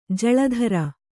♪ jaḷa dhara